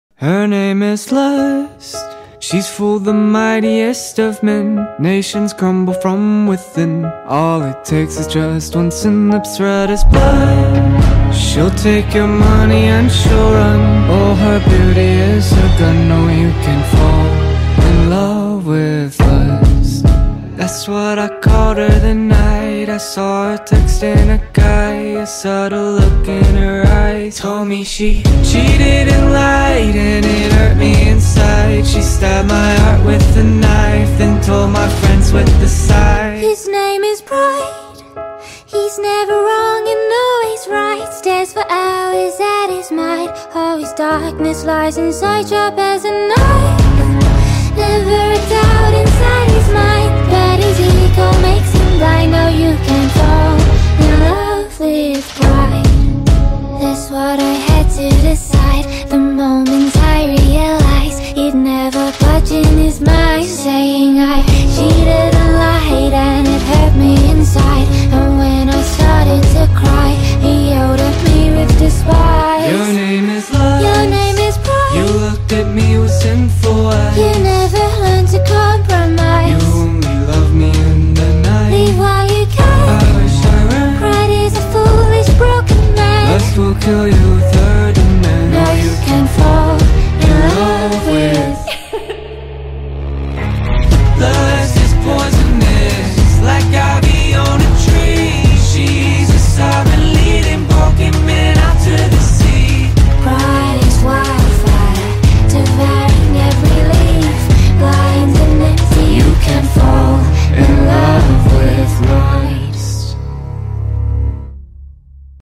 Nigerian singer-songsmith